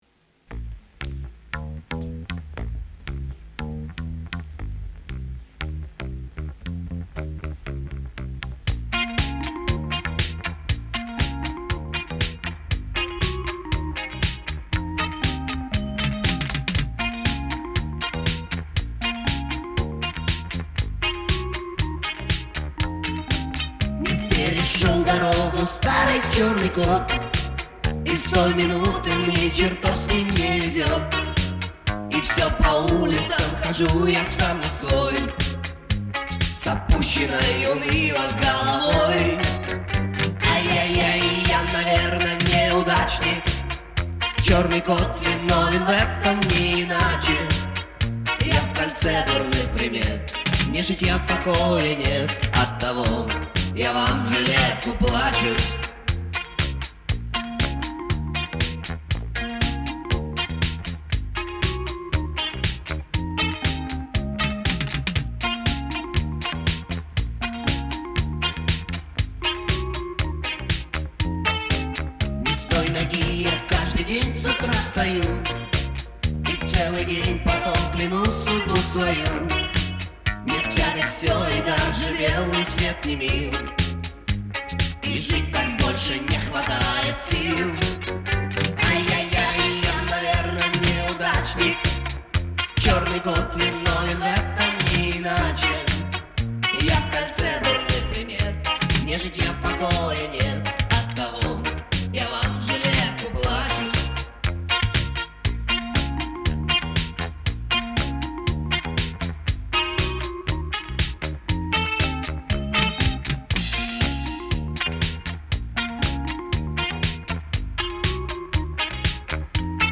Что-то типа арт-рока.